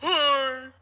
greeting-04.mp3